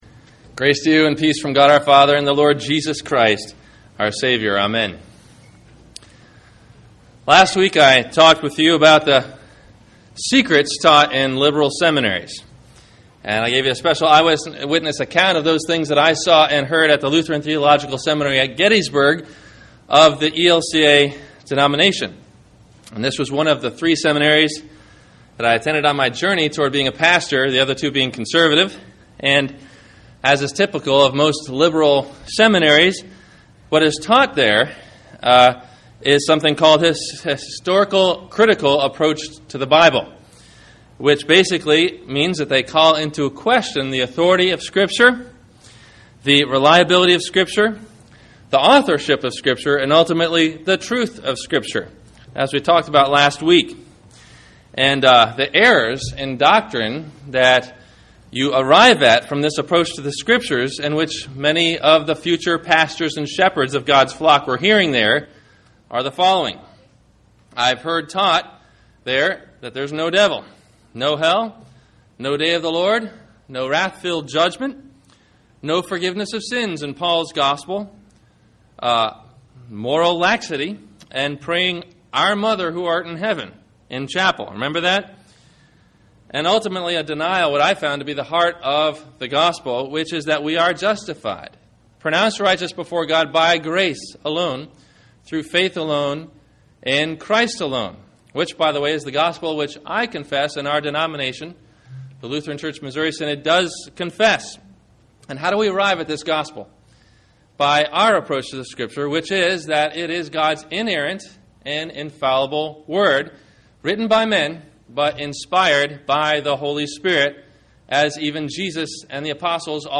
Escaping False Prophets – Sermon – July 19 2009